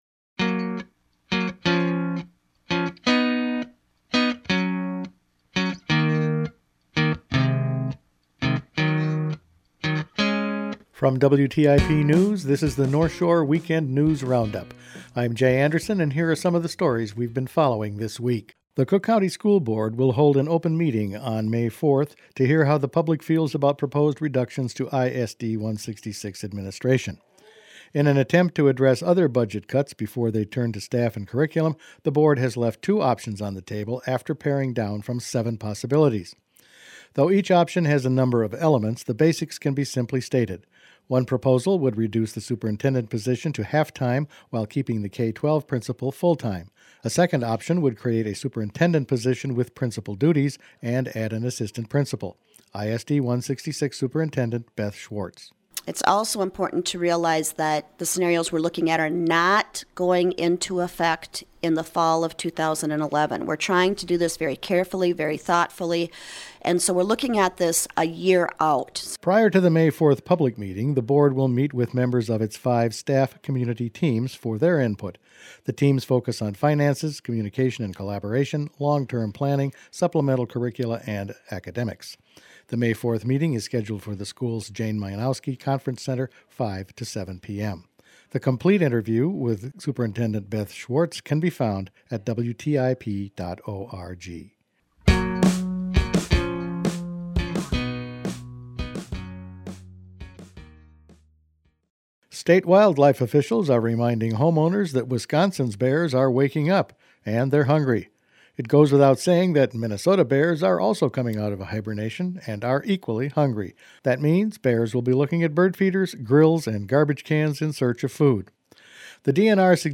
Each weekend WTIP news produces a round up of the news stories they’ve been following this week. Bears in the yard and wolves off the list; budget cuts at school and consultants on the golf course were all in this week’s news.